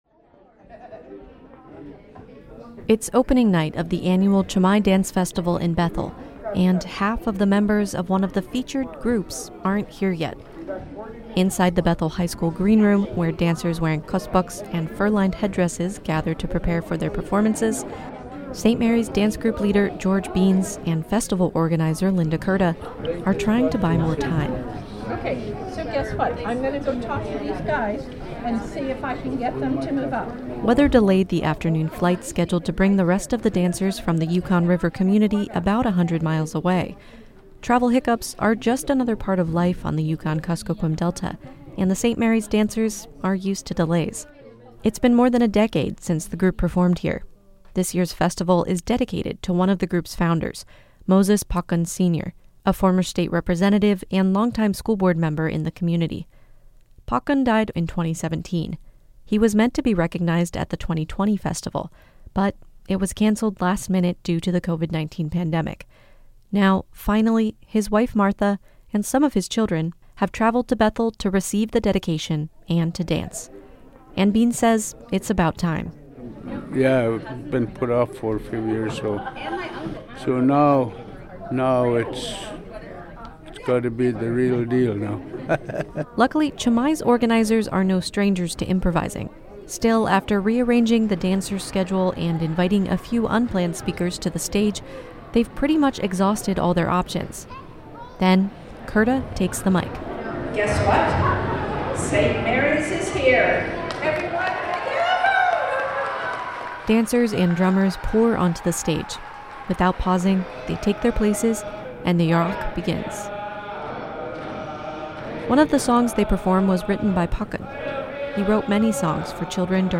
It’s the opening night of the annual Cama-i dance festival in Bethel, and most of the members of one of the featured dance groups aren’t here yet.
Applause erupts as dancers and drummers pour onto the stage.